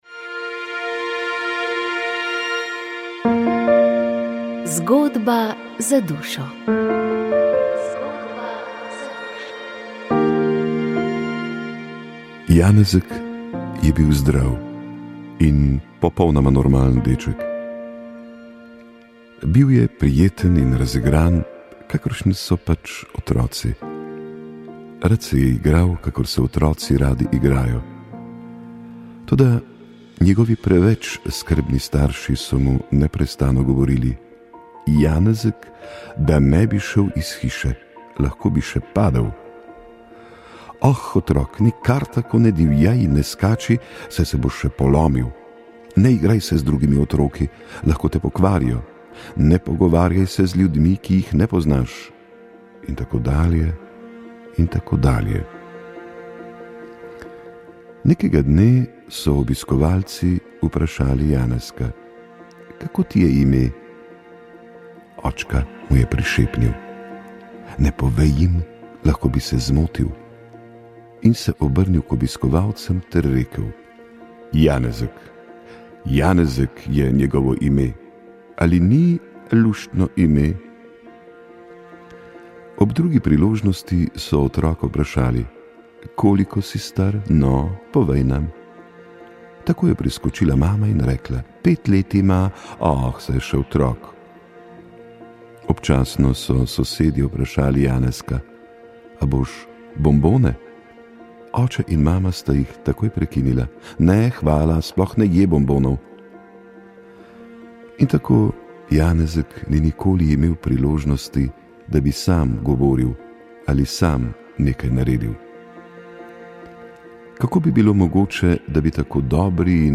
duhovnost odnosi misijon nagovor